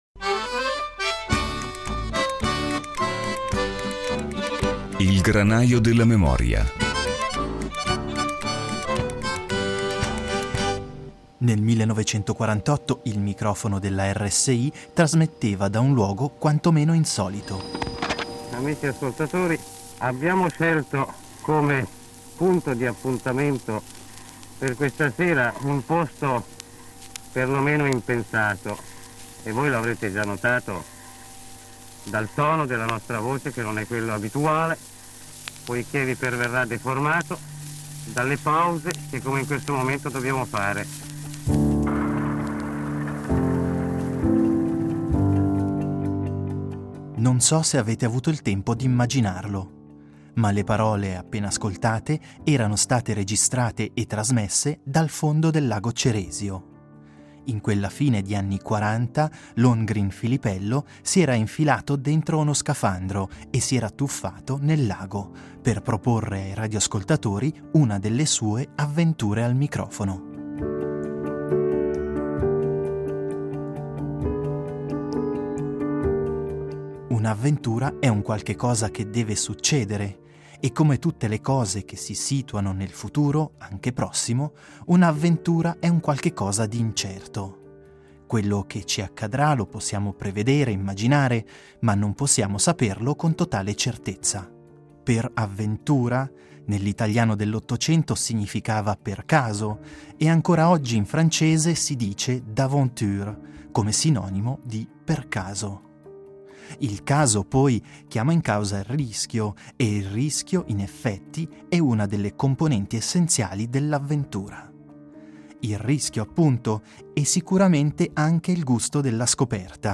Memorie avventurose questa settimana nel “Granaio della memoria”. I frammenti sonori recuperati tra le Teche della RSI ci diranno infatti di avventure e di avventurieri… di uomini o donne che si sono trovati a volere o a dover affrontare situazioni singolari e straordinarie, rischiose e affascinanti, incerte e fortuite.